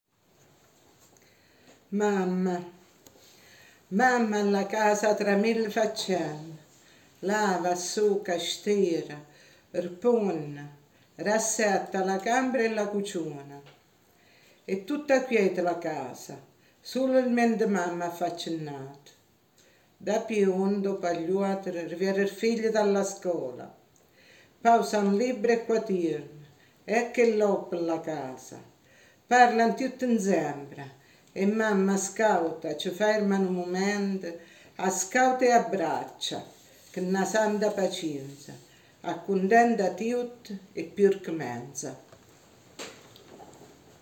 Il gruppo che recita le poesie in dialetto e che canta le due canzoni è composto da